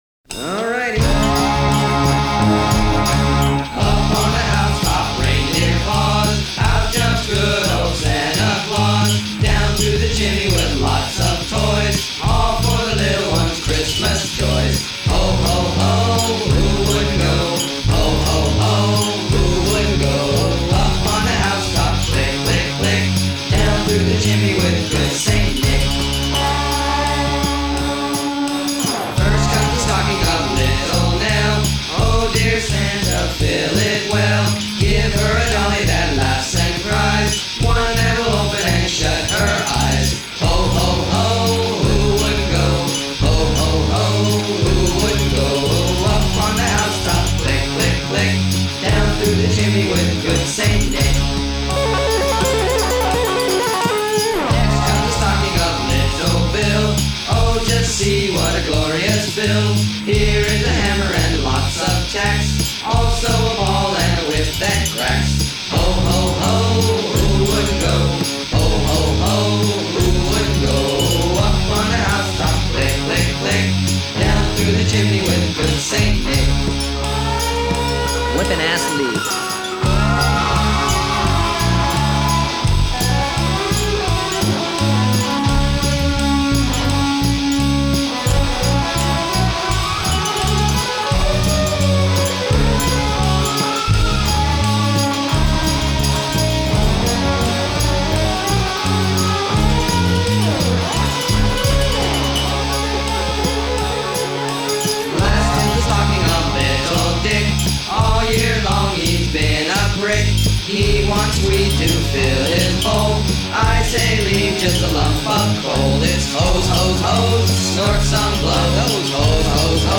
Vocals, Bass, Percussion, Cheap Casio Synth
Vocals, Guitars, Percussion
Percusssion, Emax Cello